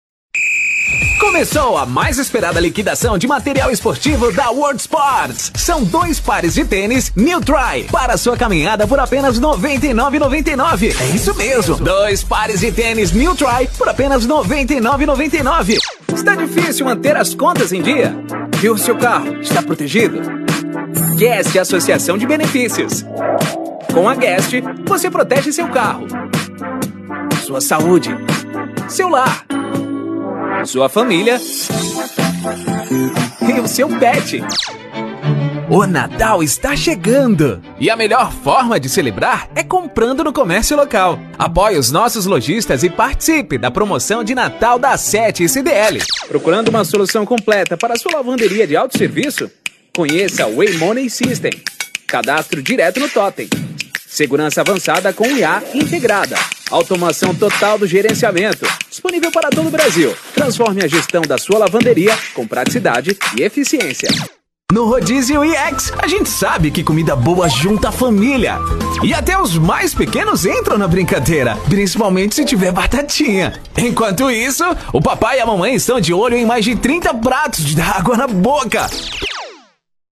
Spot Comercial
Vinhetas
VT Comercial
Impacto
Animada
Caricata